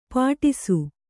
♪ pāṭisu